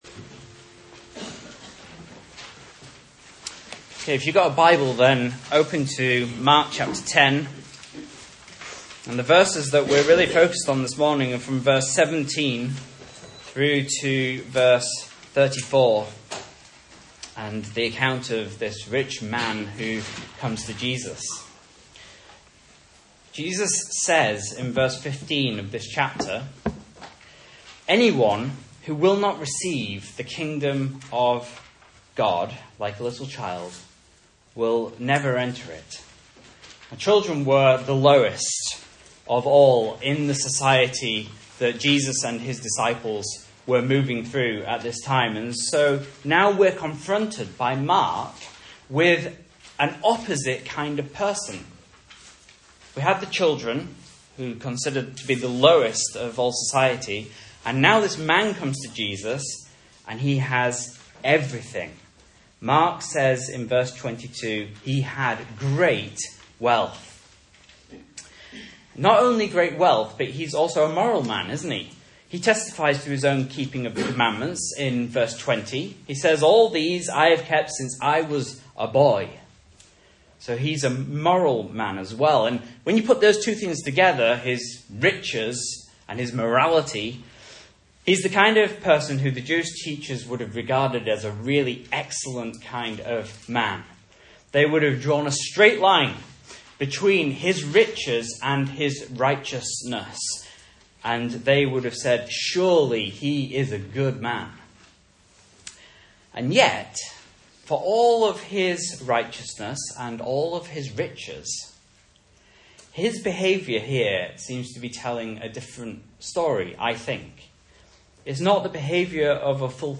Message Scripture: Mark 10:17-34 | Listen